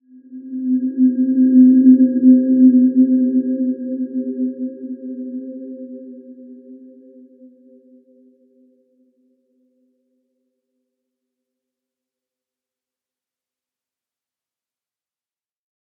Dreamy-Fifths-C4-mf.wav